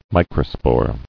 [mi·cro·spore]